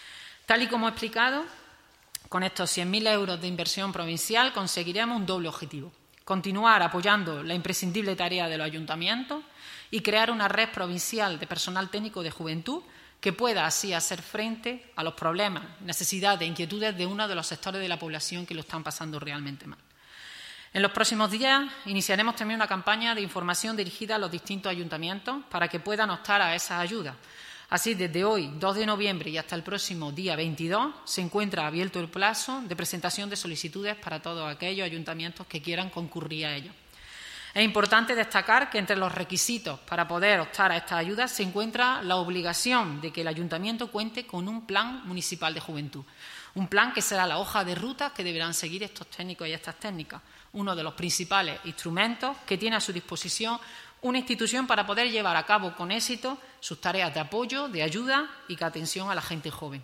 Pilar Lara explica a quién van dirigida estas ayudas